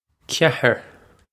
Ceathar kya-her
This is an approximate phonetic pronunciation of the phrase.